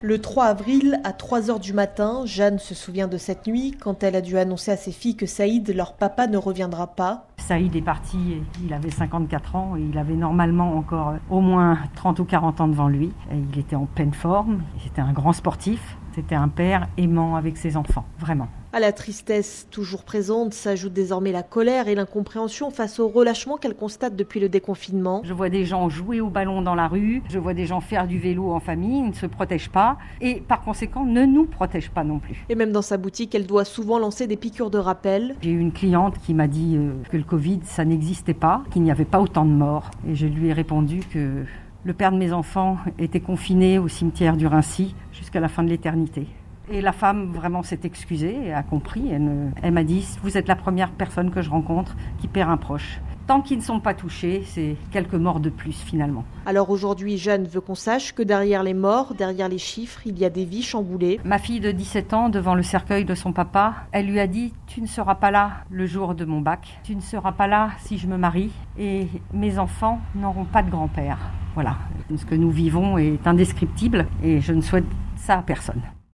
Écouter ce témoignage recueilli par radio france au sortir du confinement est un bon résumé de la situation : pas de malade ou de mort proche, moins de vigilance ou déni.
temoignagecommercantecovid.mp3